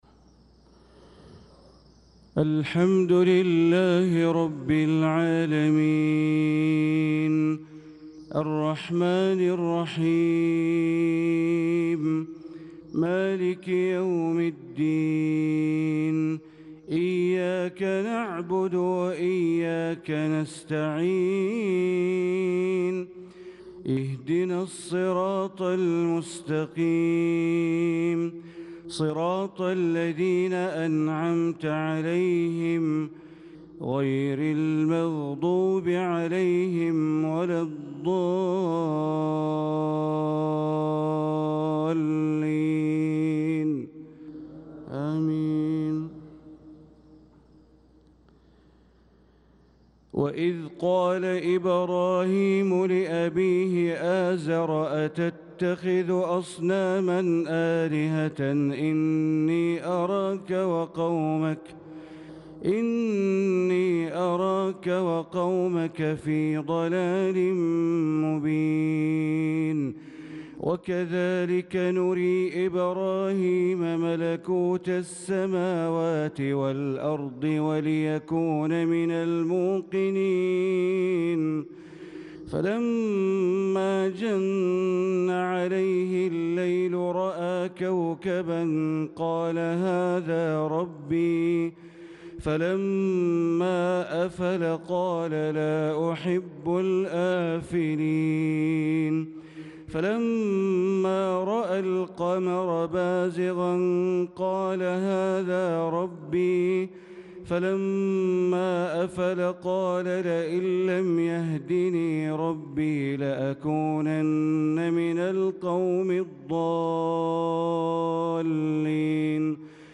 صلاة الفجر للقارئ بندر بليلة 21 ذو القعدة 1445 هـ